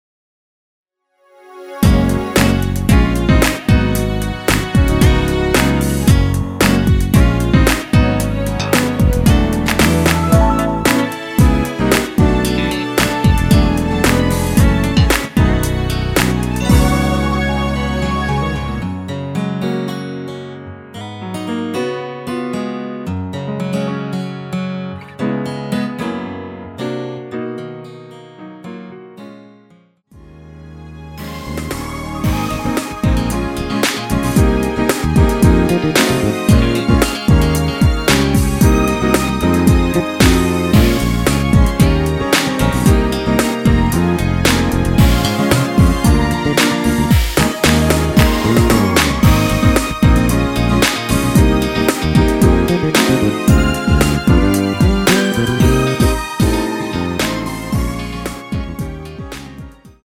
원키에서(-2)내린 MR입니다.
Gb
앞부분30초, 뒷부분30초씩 편집해서 올려 드리고 있습니다.